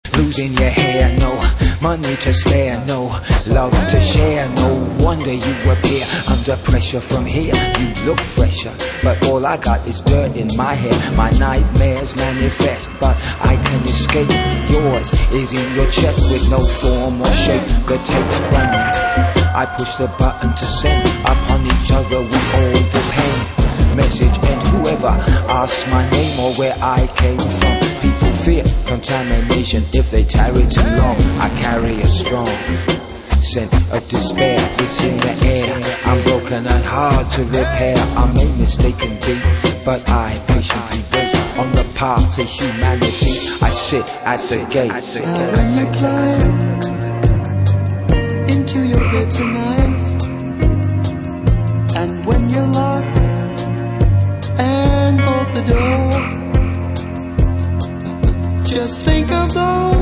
Styl: House, Lounge, Breaks/Breakbeat